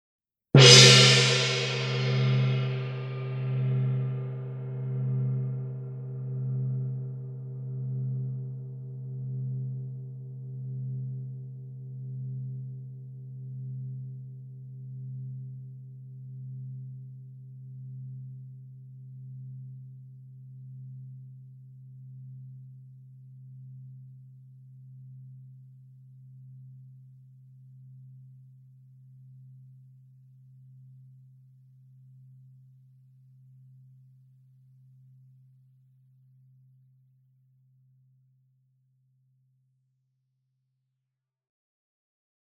PLAYTECHの20インチゴング。